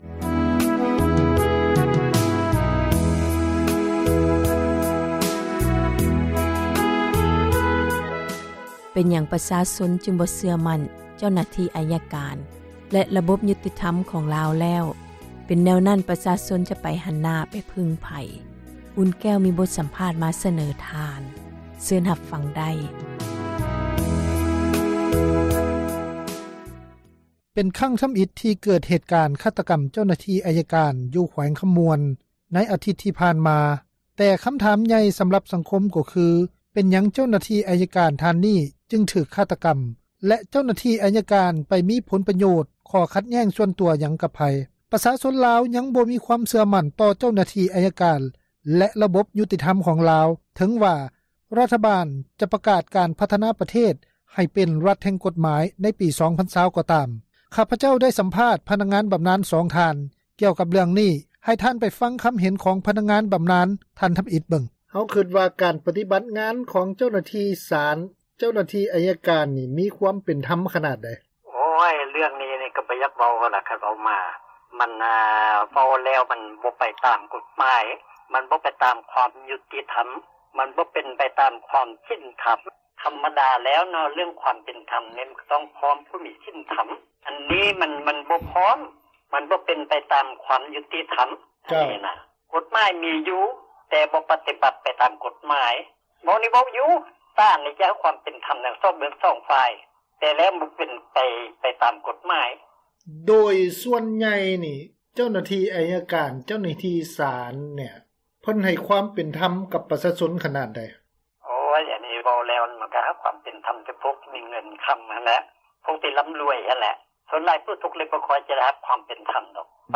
ຂ້າພະເຈົ້າໄດ້ສຳພາດ ພະນັກງານບຳນານ ສອງ ທ່ານ ກ່ຽວກັບ ເລື້ອງນີ້, ໃຫ້ທ່ານໄປຟັງ ຄຳເຫັນຂອງ ພະນັກງານບຳນານ ທ່ານ ທຳອິດເບິ່ງ.